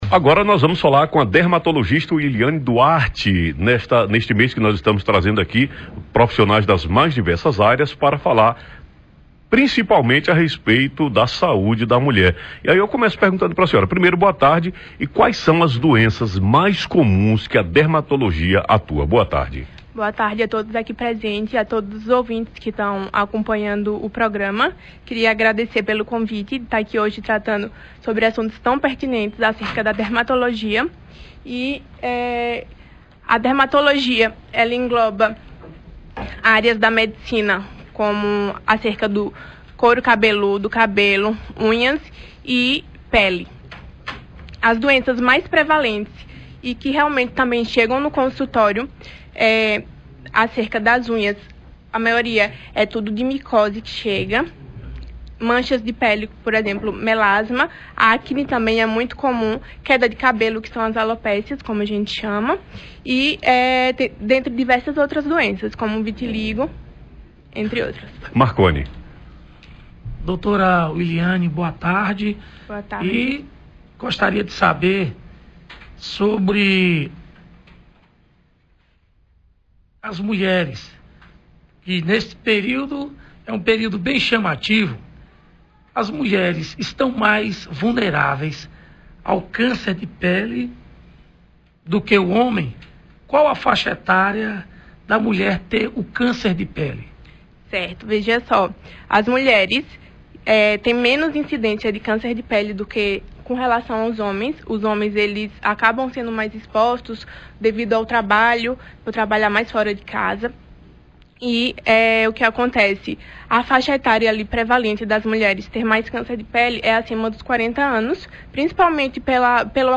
ENTREVISTA-DERMATOLOGISTA.mp3